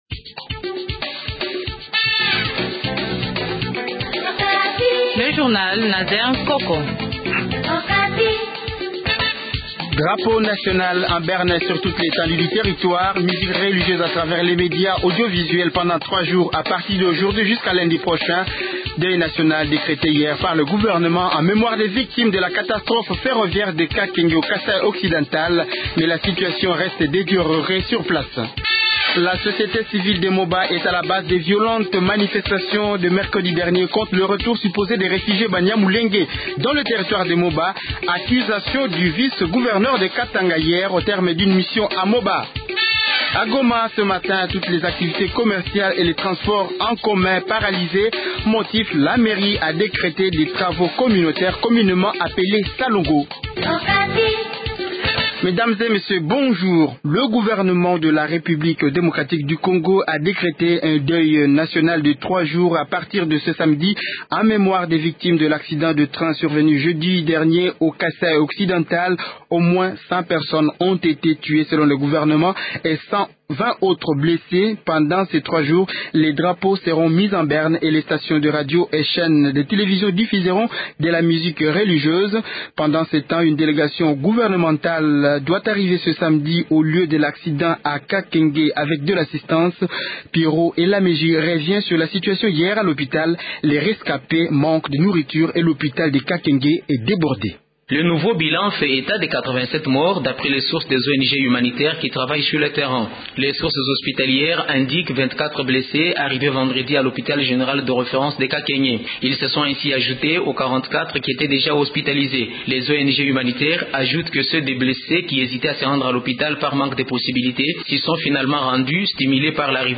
Journal Français Midi 12h00